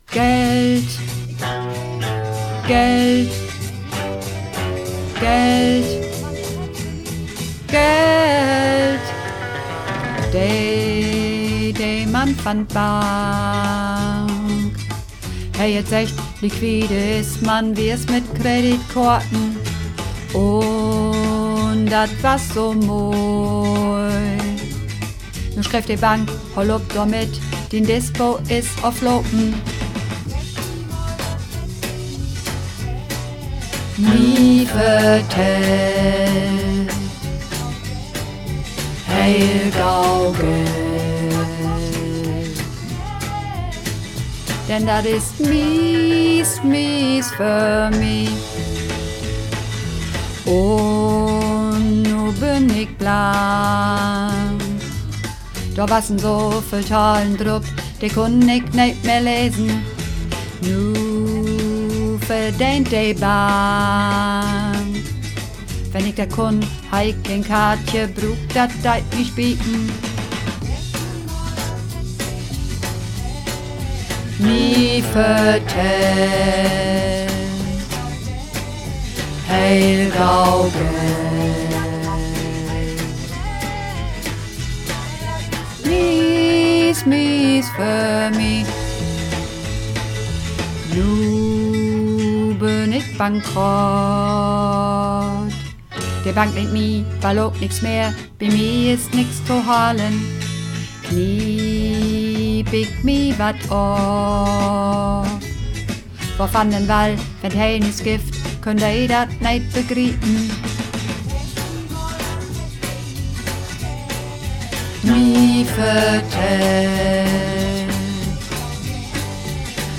Übungsaufnahmen - Geld
Runterladen (Mit rechter Maustaste anklicken, Menübefehl auswählen)   Geld (Bass und Männer)
Geld__2_Bass_und_Maenner.mp3